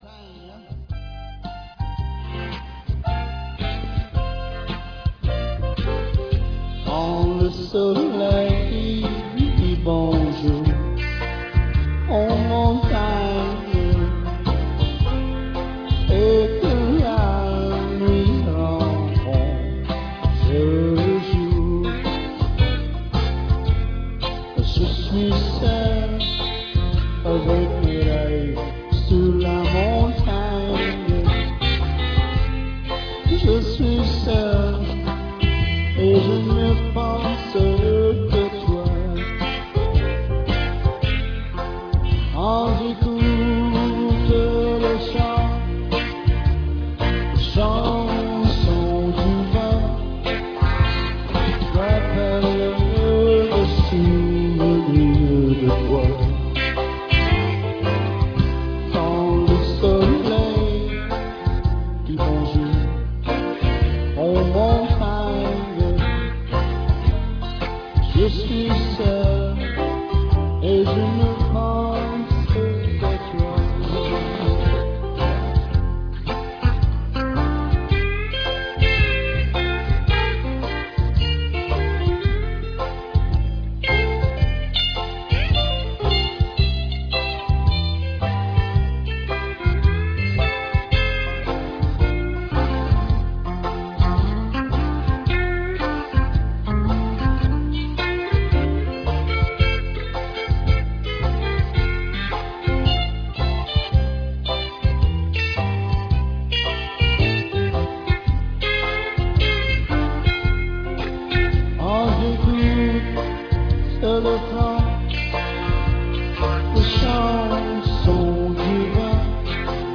VOICI UN PEU DE CHANSON QUE JE FAIS DANS LES BARS AU QUEBEC